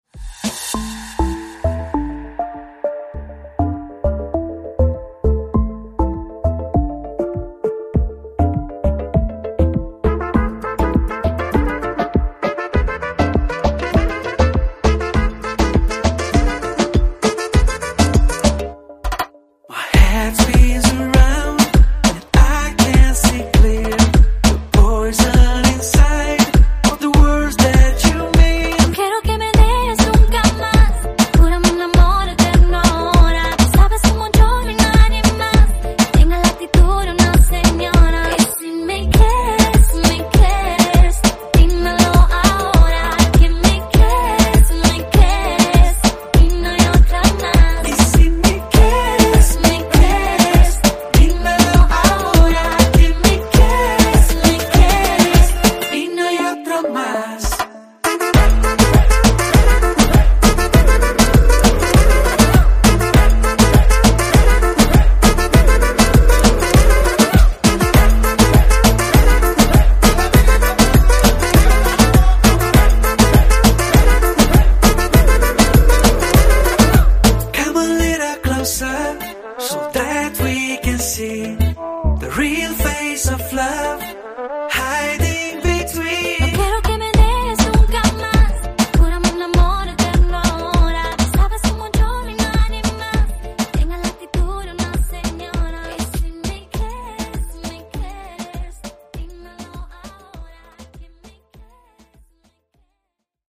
Genre: RE-DRUM Version: Clean BPM: 120 Time